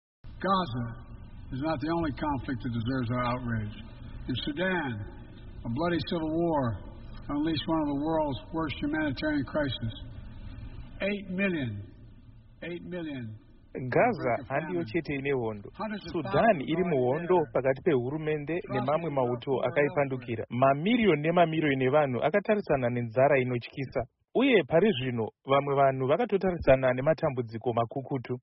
Vakataura mashoko aya pamberi pegungano reUnited Nations General Assembly kanova kekupedzisira kutaura pamberi pegungano iri sezvo vave kuenda pamudyandigere mushure mekutungamira kwemakore mana chete.